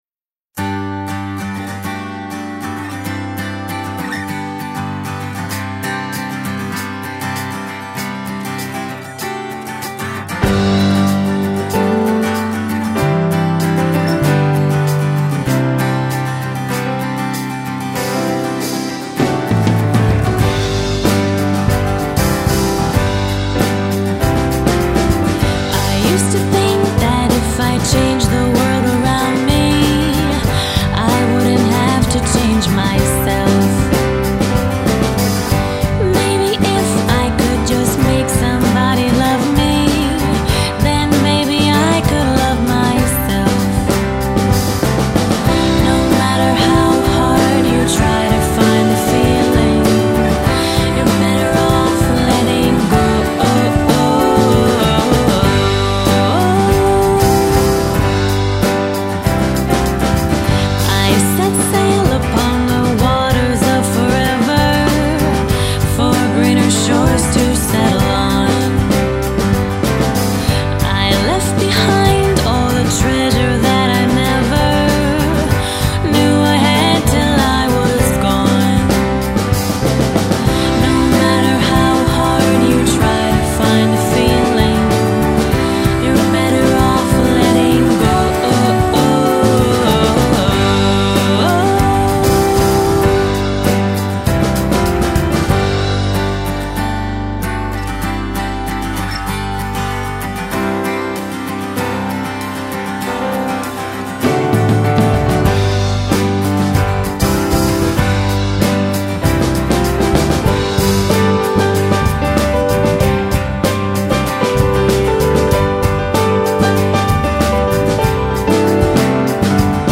acoustic guitar
piano
bass
drums
electric guitar